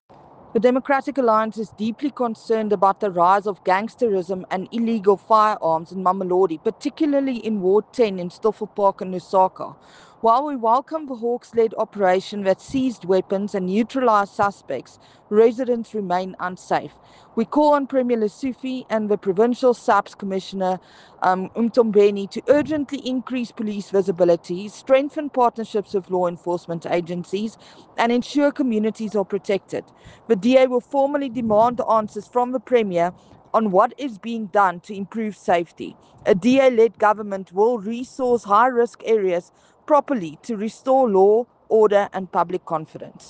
Afrikaans soundbites by Crezane Bosch MPL.